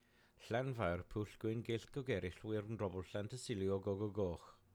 LlanfairPG.wav